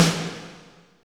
47.05 SNR.wav